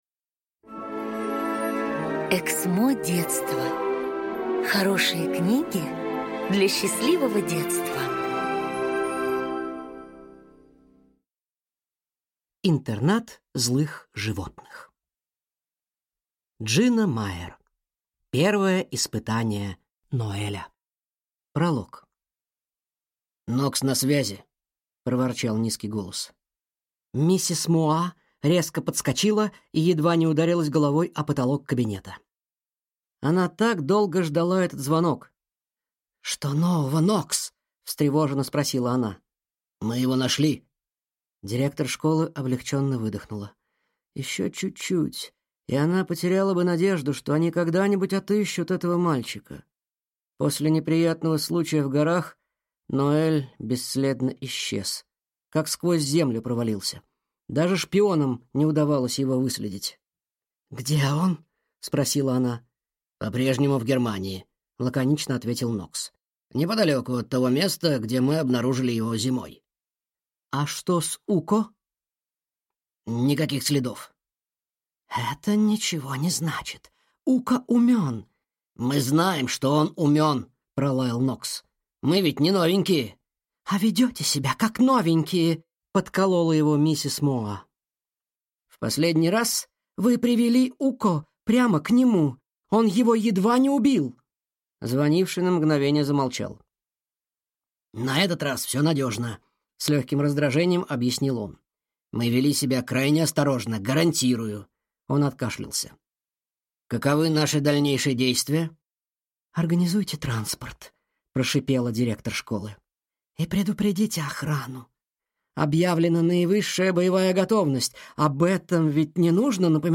Аудиокнига Первое испытание Ноэля | Библиотека аудиокниг